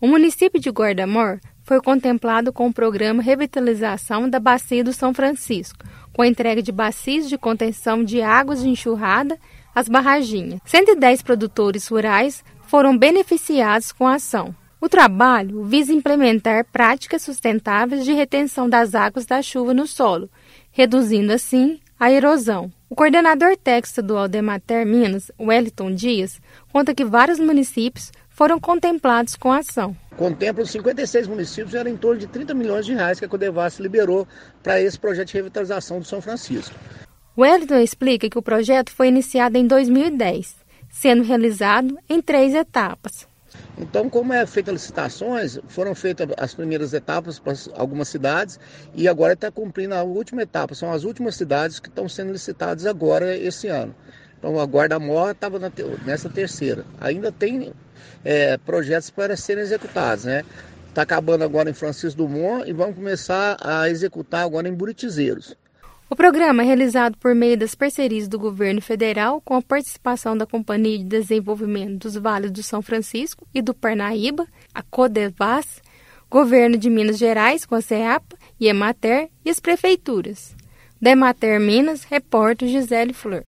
Município recebeu investimento de R$ 480 mil e a estimativa é de que 110 produtores rurais sejam beneficiados. Ouça matéria de rádio.